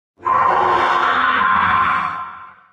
255081e1ee Divergent / mods / Soundscape Overhaul / gamedata / sounds / monsters / poltergeist / die_1.ogg 16 KiB (Stored with Git LFS) Raw History
die_1.ogg